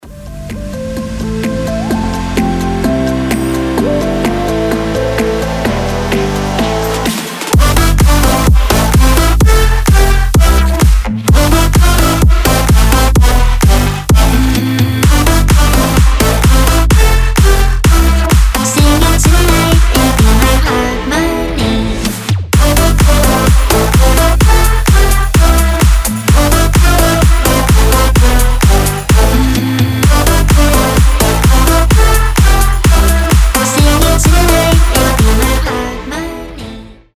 • Качество: 320, Stereo
громкие
Electronic
EDM
энергичные
Позитивный, энергичный future house